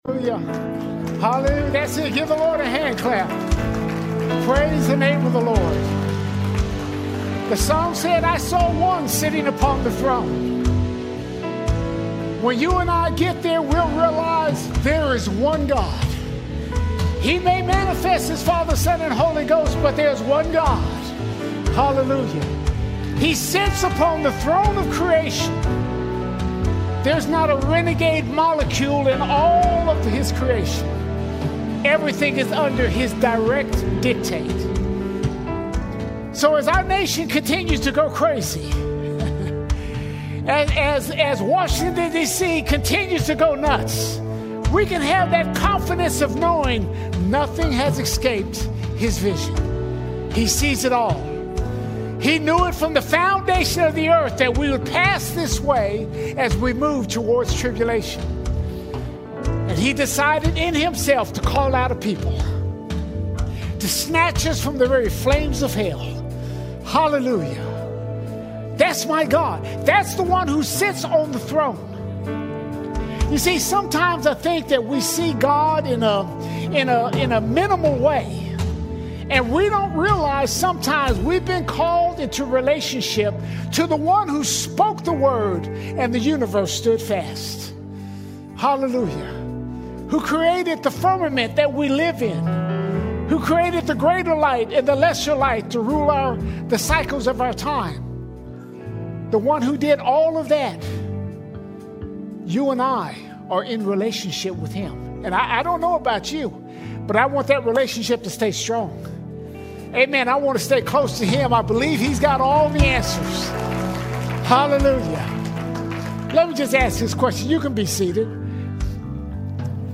2 February 2026 Series: Sunday Sermons All Sermons The Righteousness Trap The Righteousness Trap Although believers are called to grow in righteousness, it can become a trap when measured against others.